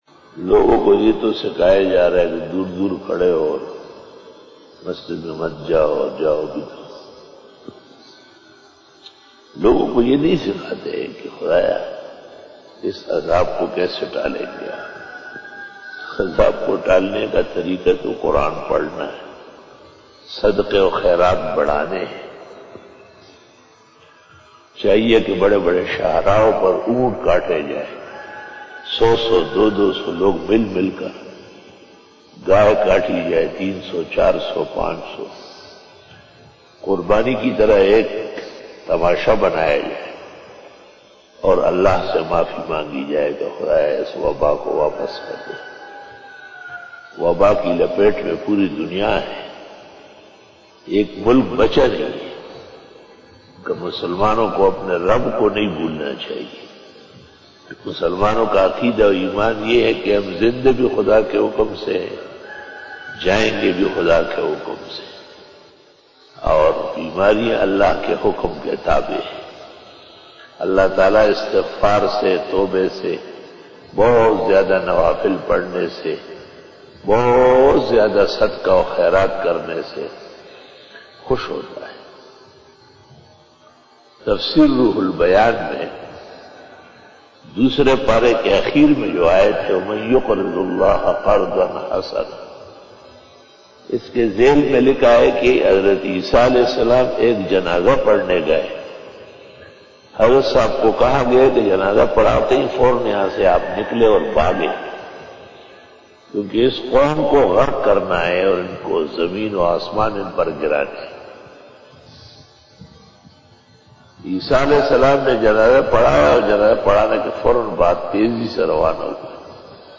After Namaz Bayan
بیان بعد نماز فجر بروز جمعرات